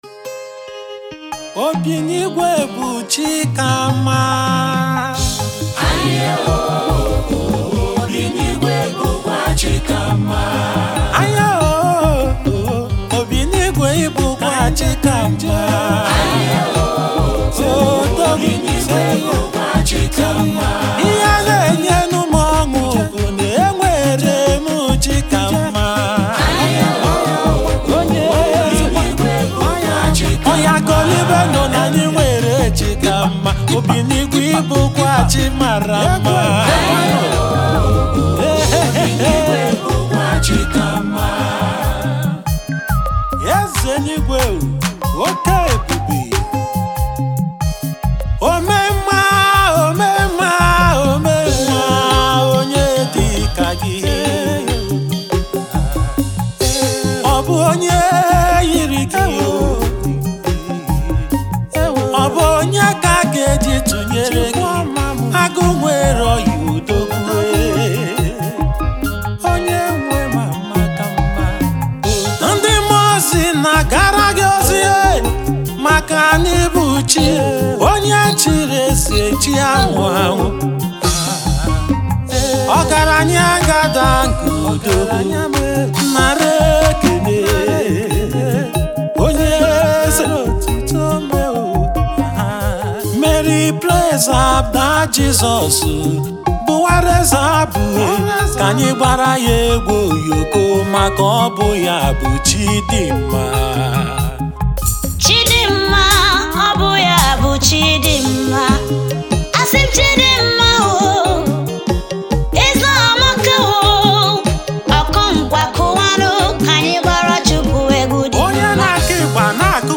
A melodious sound
Gospel
Nigeria gospel music